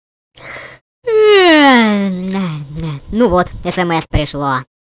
sms_prishlo.mp3